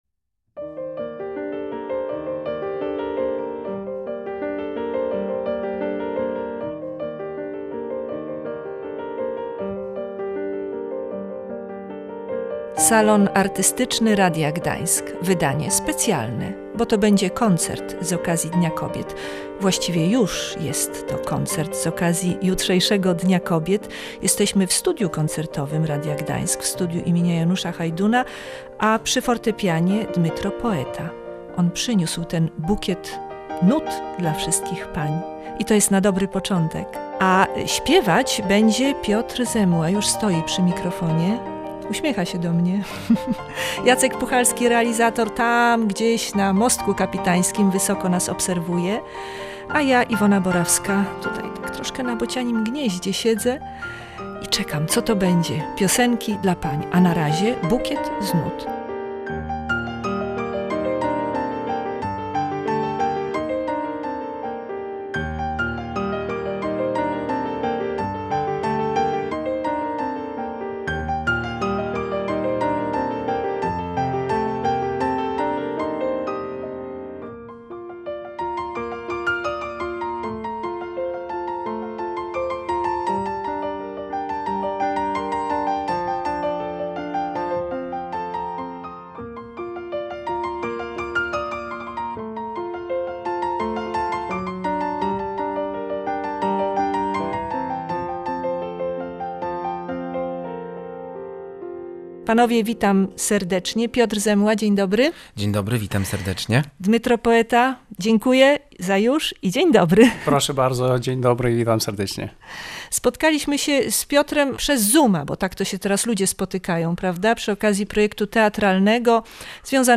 wokalista
fortepianie
Koncert odbył się w Studiu im. Janusza Hajduna.